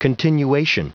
Prononciation du mot : continuation